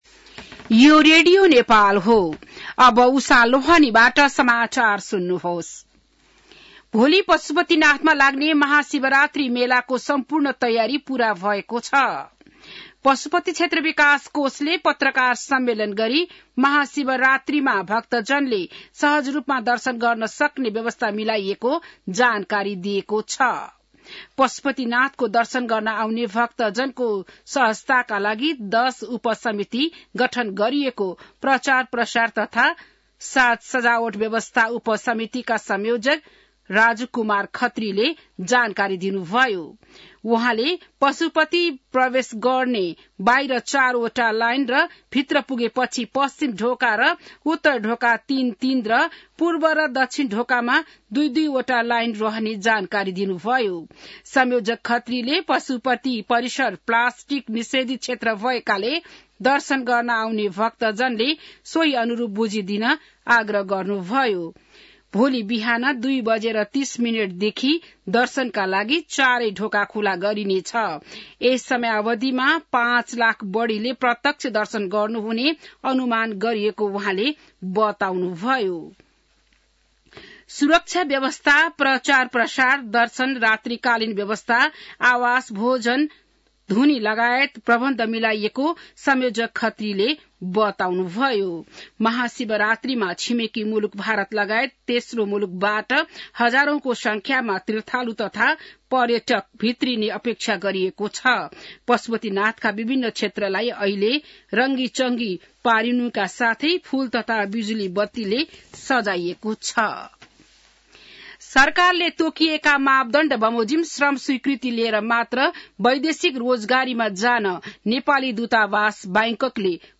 An online outlet of Nepal's national radio broadcaster
बिहान १० बजेको नेपाली समाचार : १४ फागुन , २०८१